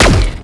Zapper_1p_03.wav